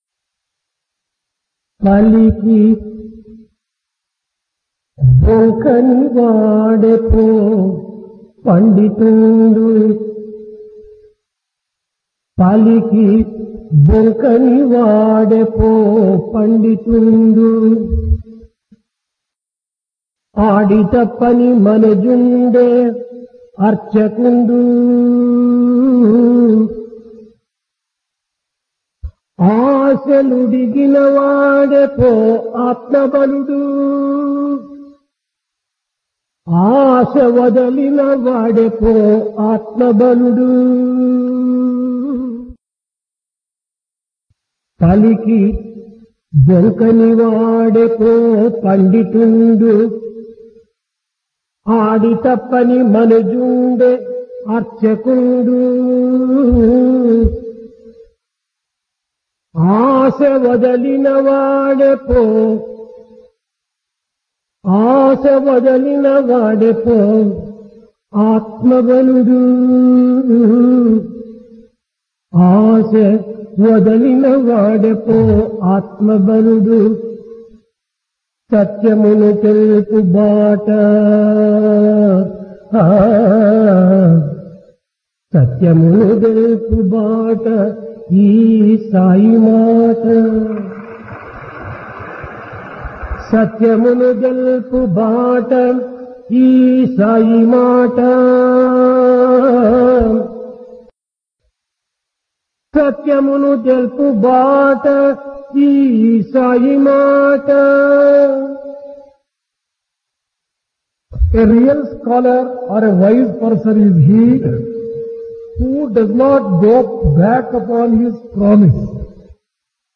Dasara - Divine Discourse | Sri Sathya Sai Speaks
Place Prasanthi Nilayam Occasion Dasara